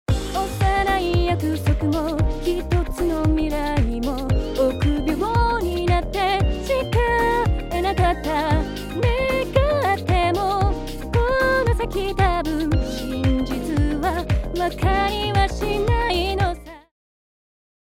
使用前の音源では、音量のばらつきやブレス音が目立っていますが、DynAssistを適用すると音量が均一になりブレス音が自然に処理され、聴きやすいサウンドに仕上がりました。